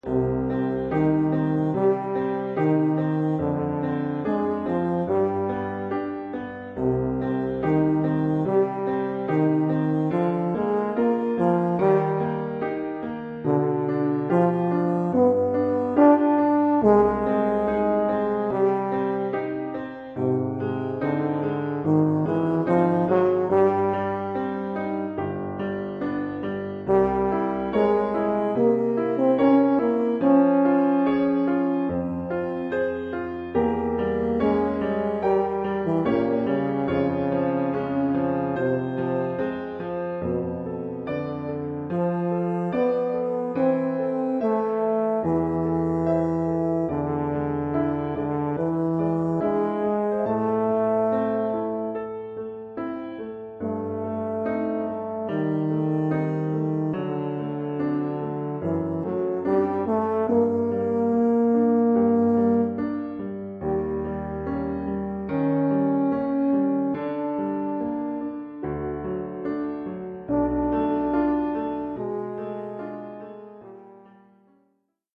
euphonium / tuba et piano.